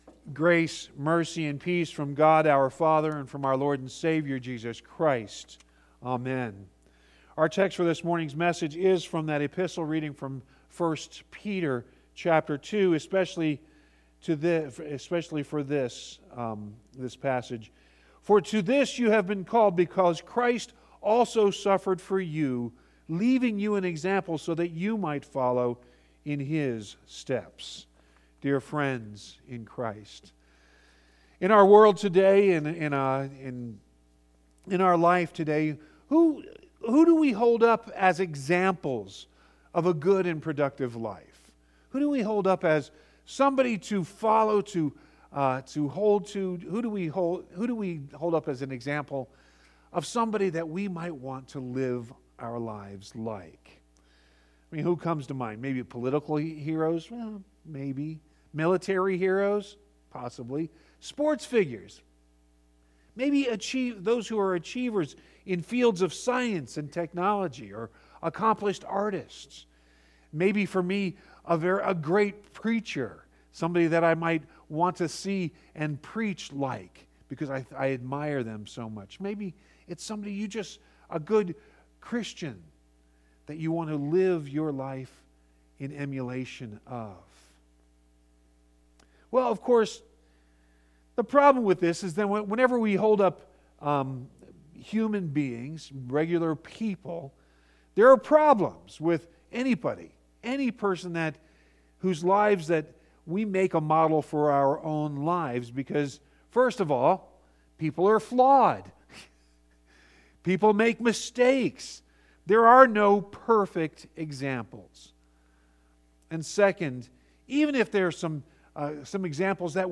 You can also download the audio sermon directly HERE, or get all the audio sermons on your phone by subscribing to our Podcast HERE.